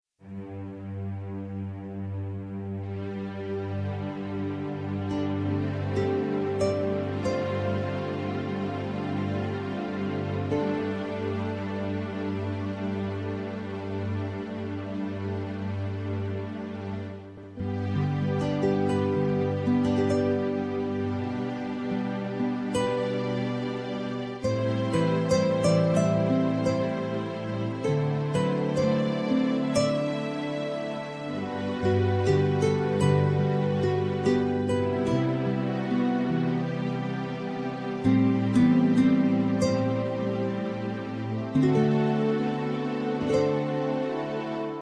+ 3A Subliminal mit Musik
+ 3A Silent Subliminal mit Murmeln